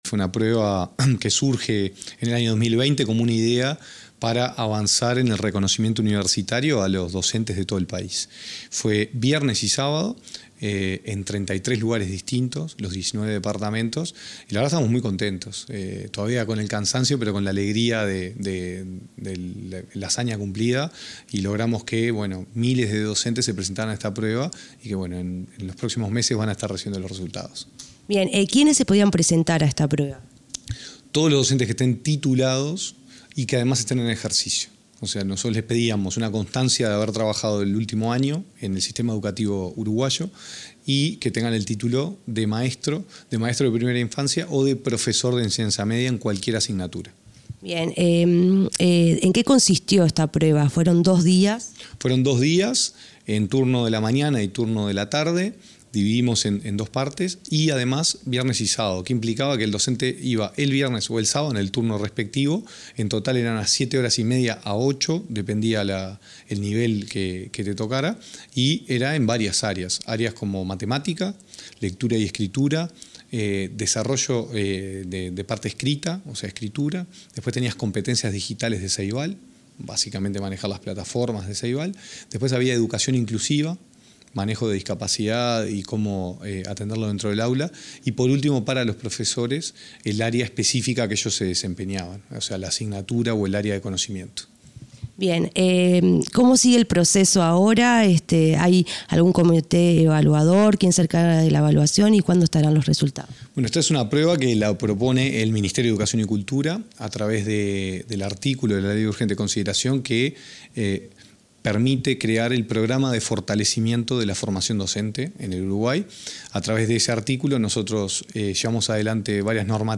Entrevista al director nacional de Educación, Gonzalo Baroni